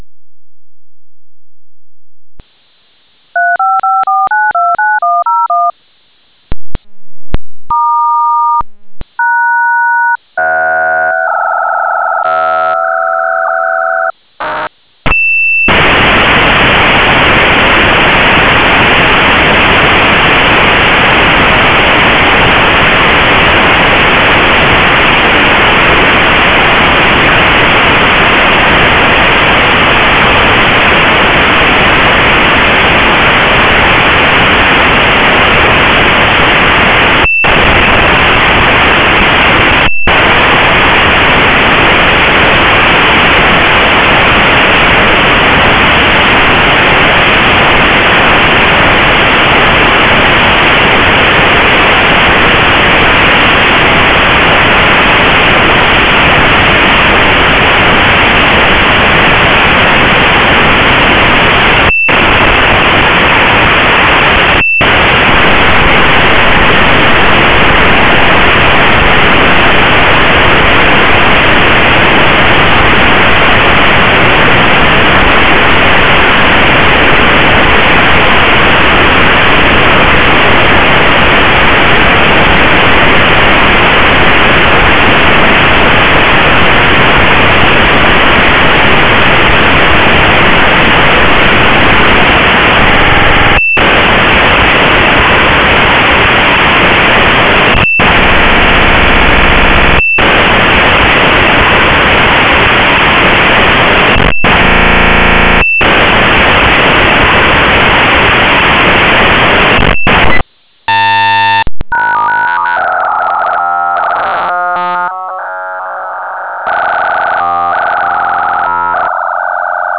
modem.wav